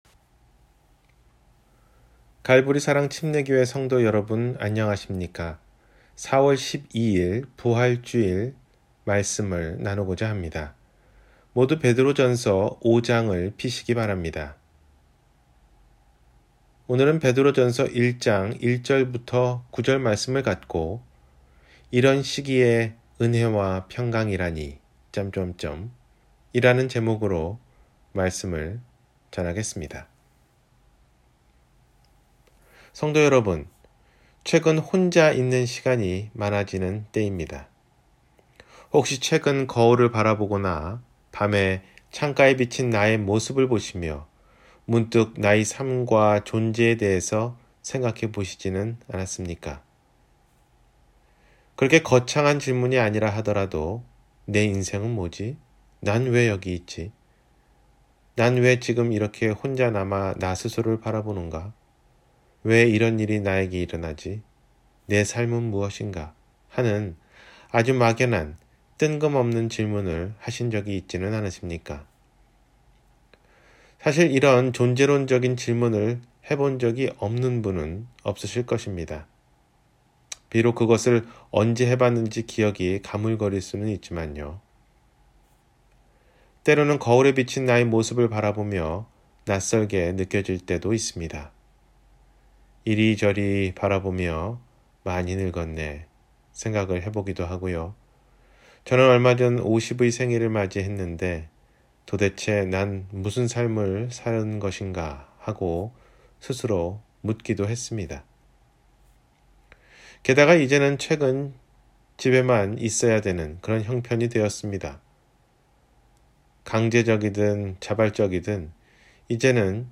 이 시기에 은혜와 평강이라니… – 주일설교